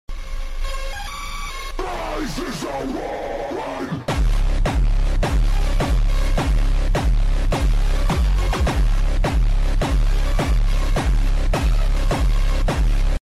a piece from the biggest auto festival in Moldova